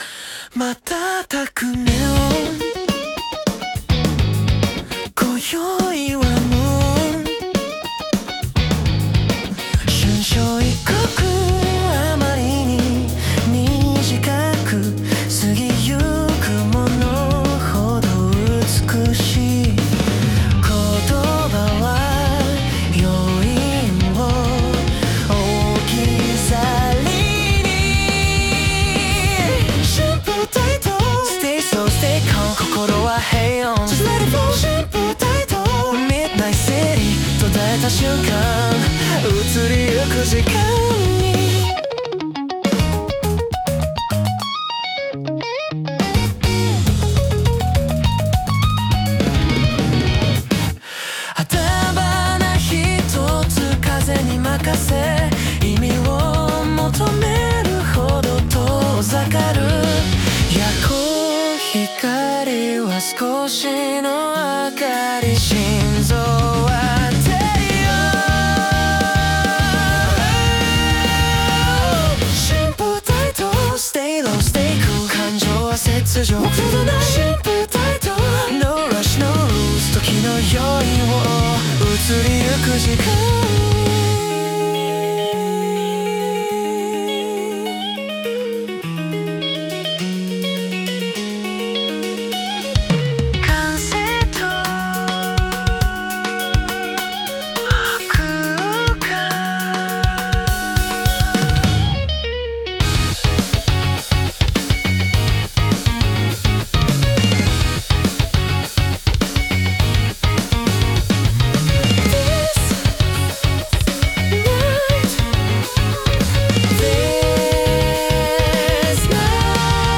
男性ボーカル
イメージ：J-ROCK,J-POP,男性ボーカル,かっこいい,切ない